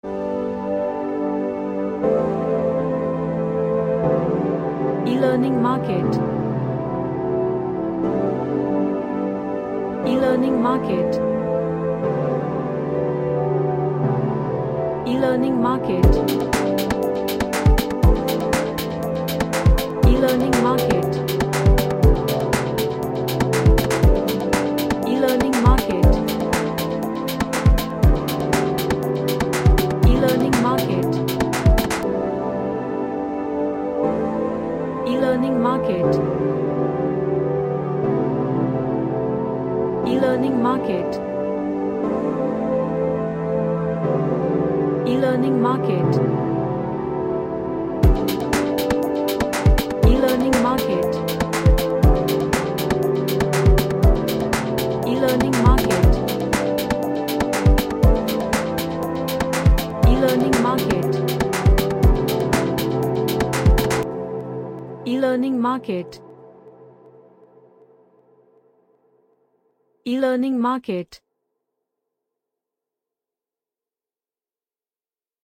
An Ambient Track with nice groovy Touch.
Emotional